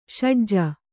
♪ ṣaḍja